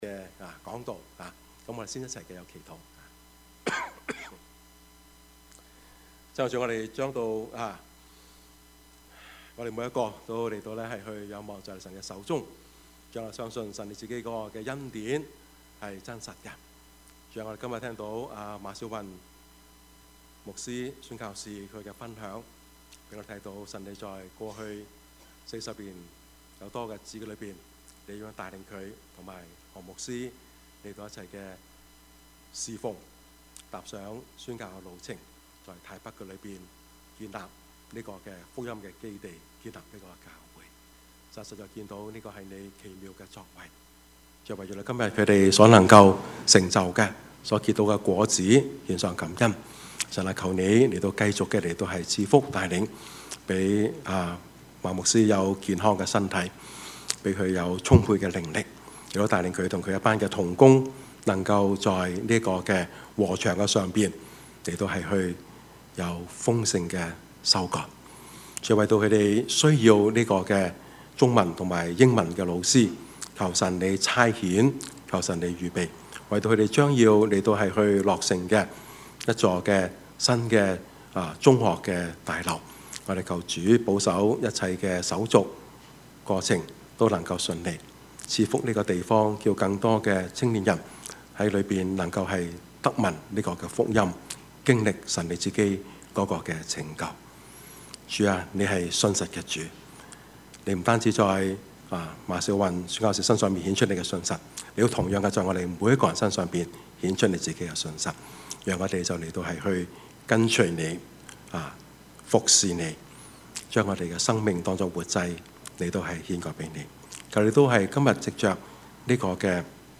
Service Type: 主日崇拜
Topics: 主日證道 « 當神開路時 來吧!